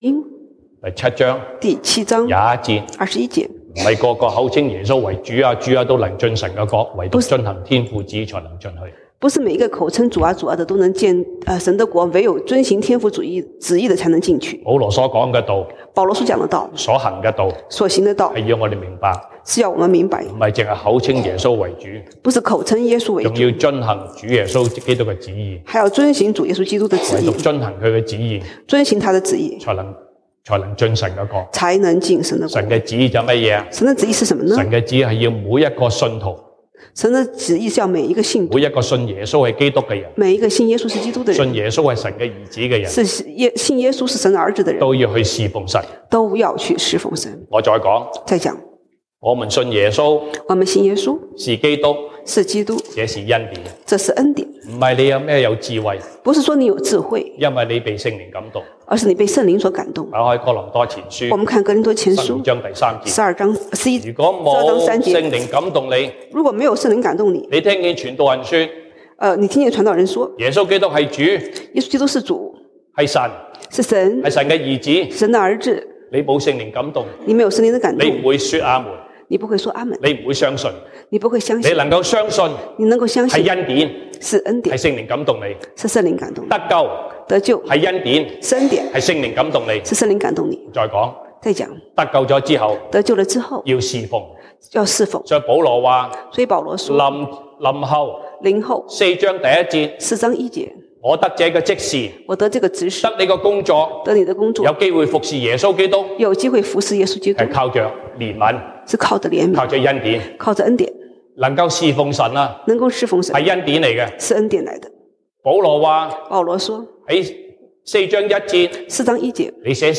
西堂證道(粵語/國語) Sunday Service Chinese: 我們有這寶貝放在瓦器裡